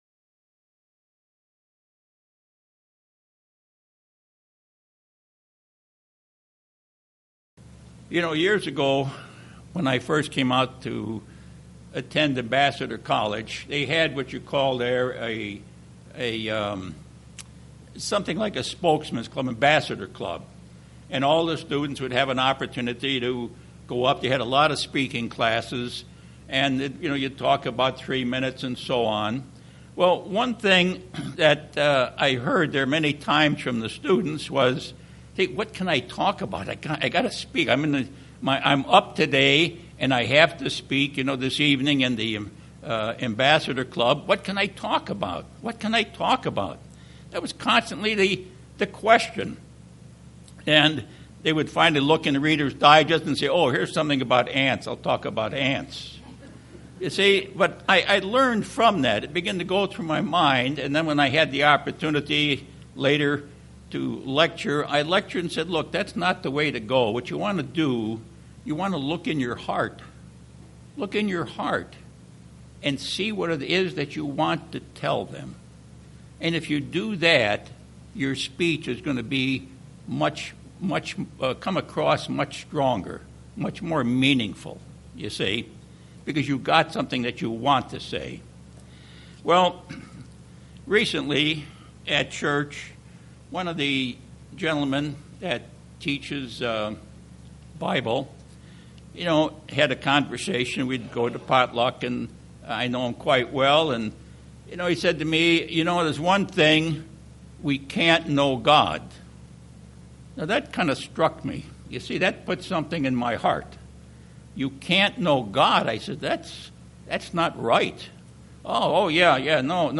This sermon examines how the Bible shows that we can know God the Father and Jesus Christ his only begotten son and how we need to base our beliefs on the Bible and not on the creeds or theories of men.